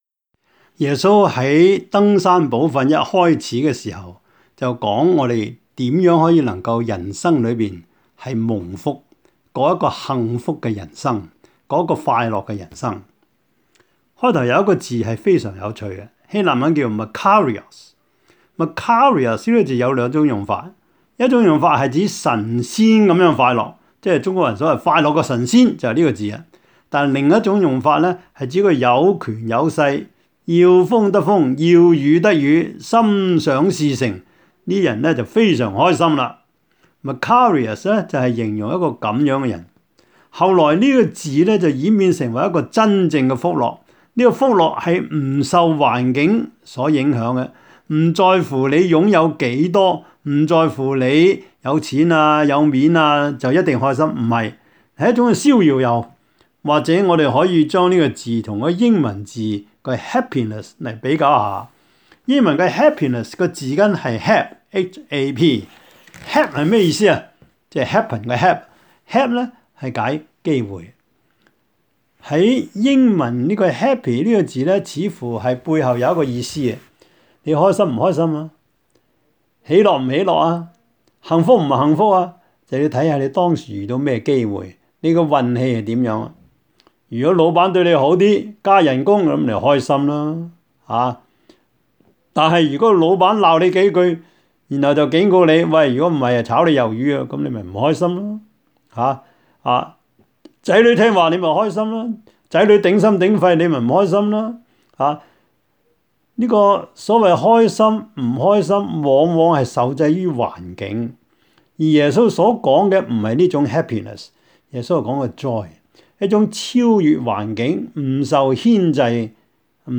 2020 Radio Talk 愛回家